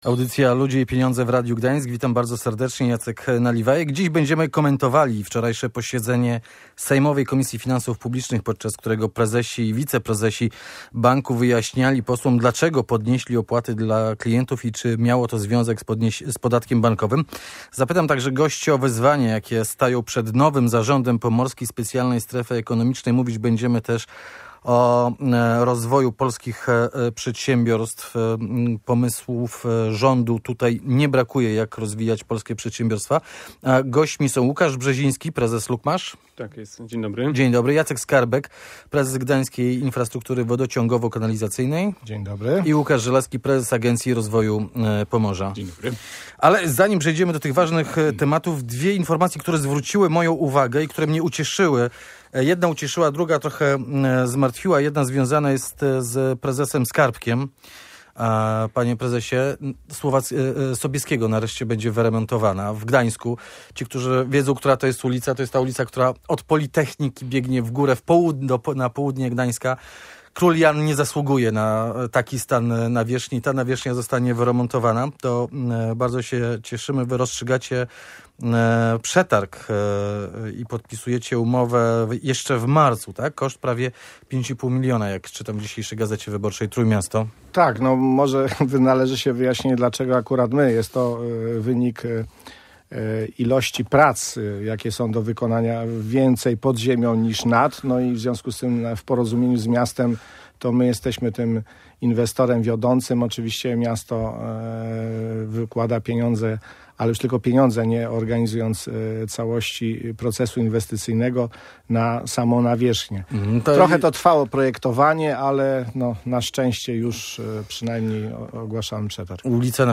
Na te pytania odpowiadali goście audycji Ludzie i Pieniądze.